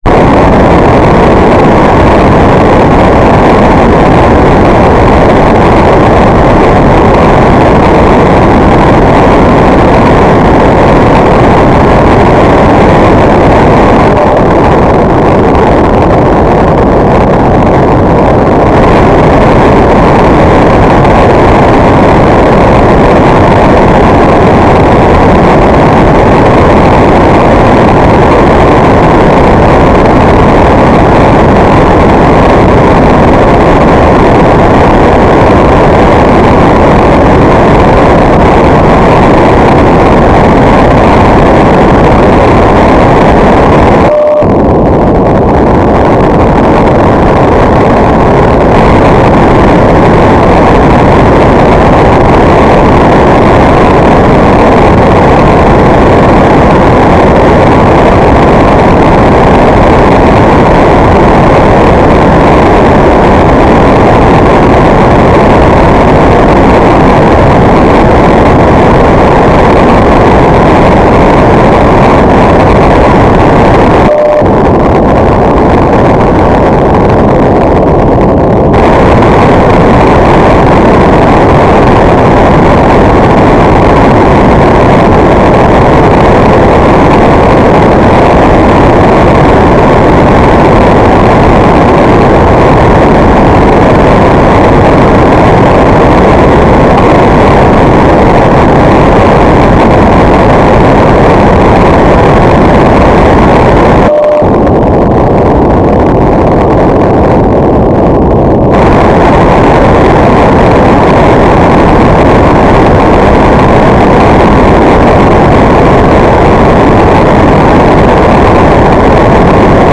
"transmitter_description": "Mode U - FSK1k2 AX.100",
"transmitter_mode": "FSK AX.100 Mode 5",